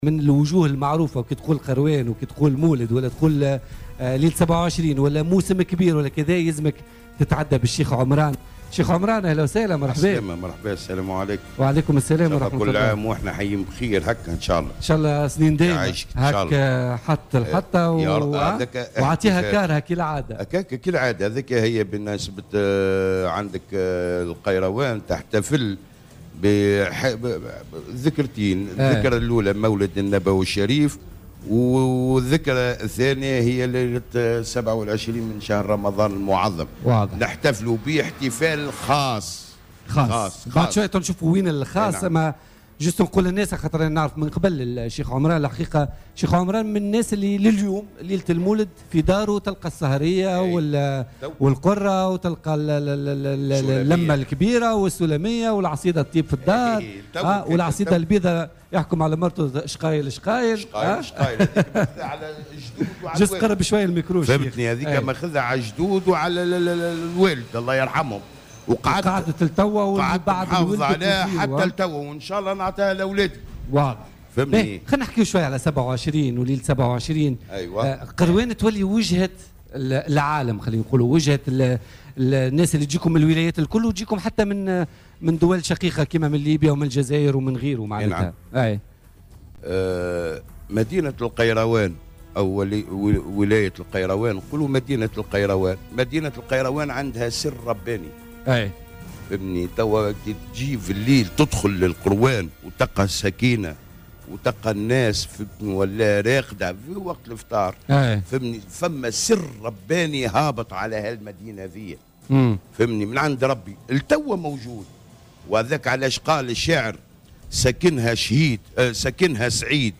تغطية خاصة من القيروان بمناسبة ليلة 27 من رمضان
تحوّل فريق برنامج "بوليتيكا" اليوم إلى مدينة القيروان التي تشهد احتفالات دينية خاصة وحركية اقتصادية كبيرة ليلة 27 من شهر رمضان.